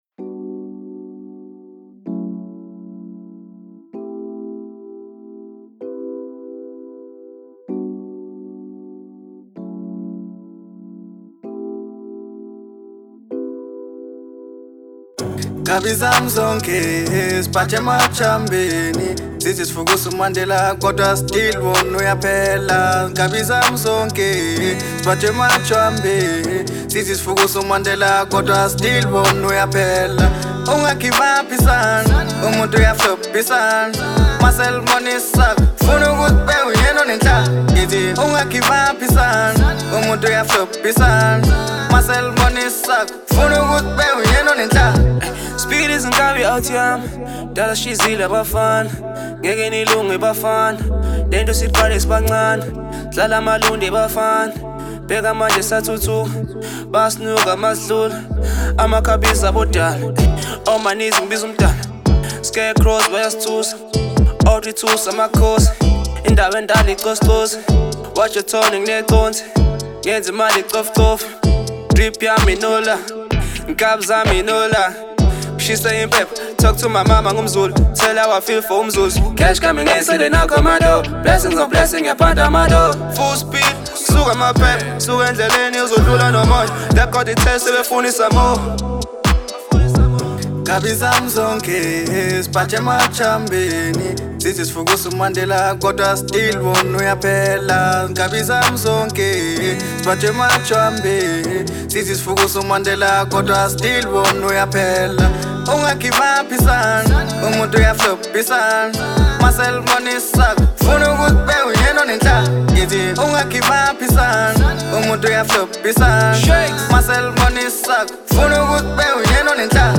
unique Rnb genre music